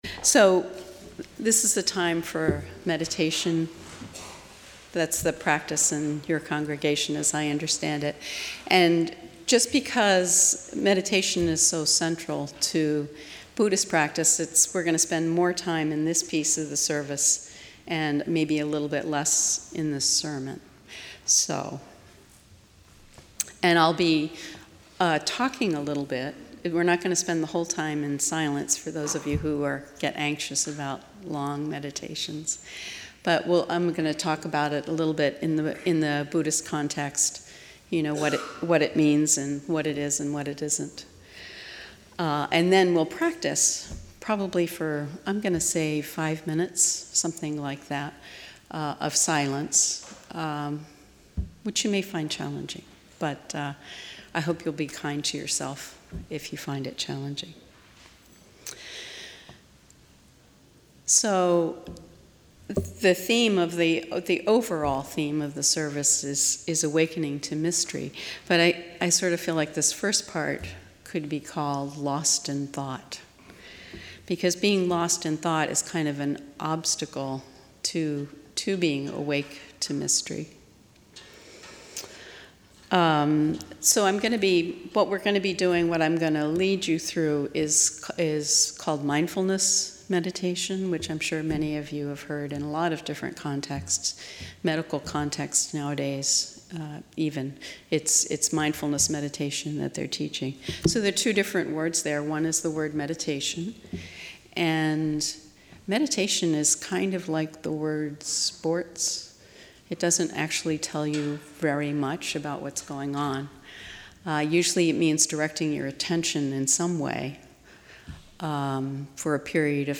Our guest speaker